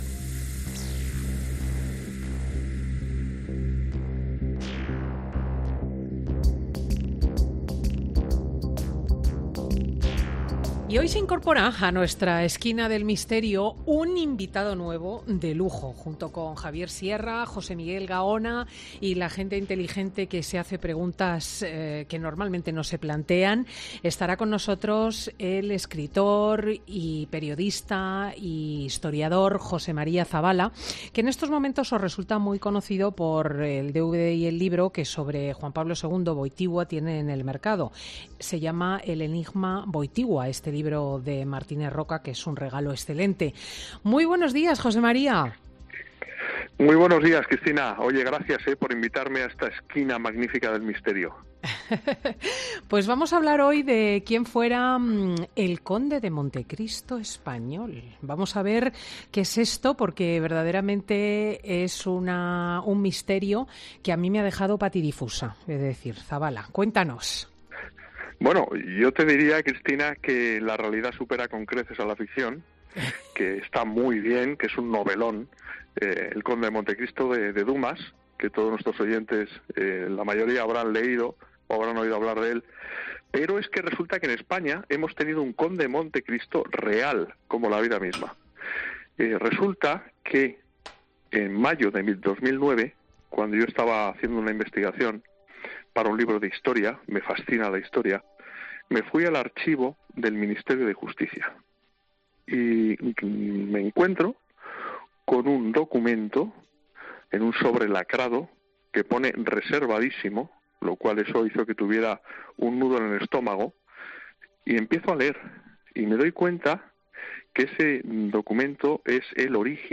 Si quieres escuchar la entrevista completa, no te la pierdas con Cristina López Schlichting en Fin de Semana.